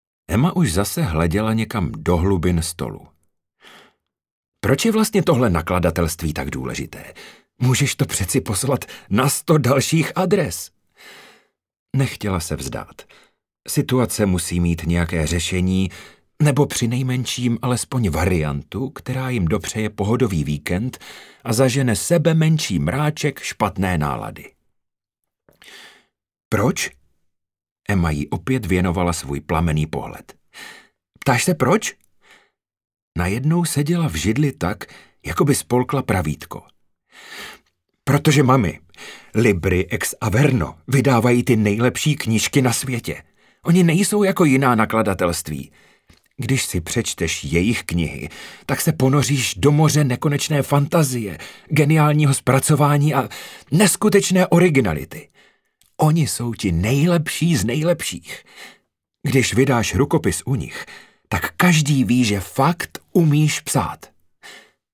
Audio povídka: